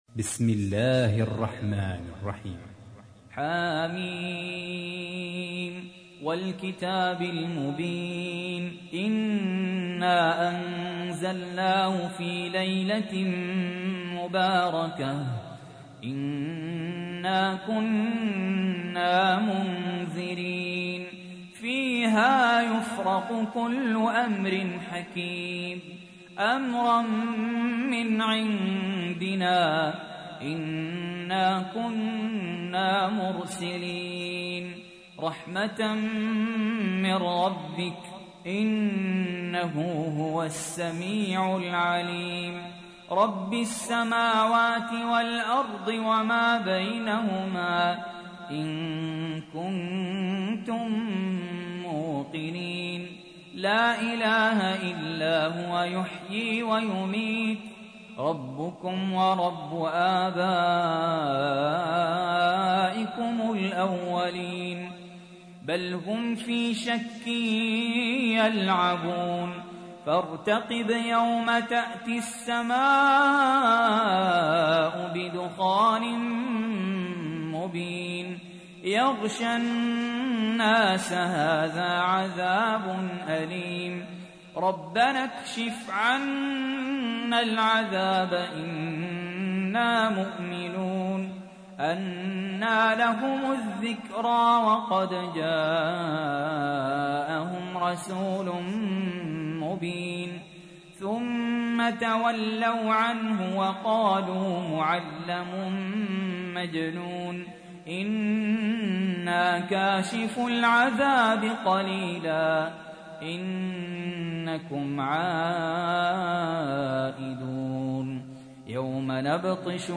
تحميل : 44. سورة الدخان / القارئ سهل ياسين / القرآن الكريم / موقع يا حسين